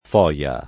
[ f 0L a ]